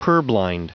Prononciation du mot purblind en anglais (fichier audio)
Prononciation du mot : purblind